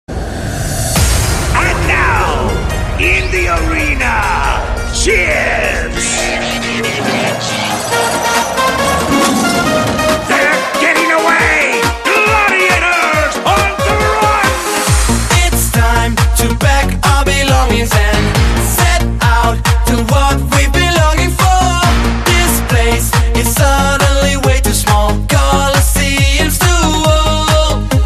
DJ铃声 大小
DJ舞曲